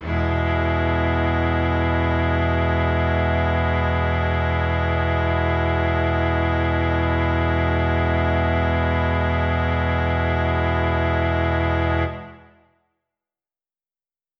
SO_KTron-Cello-Emaj.wav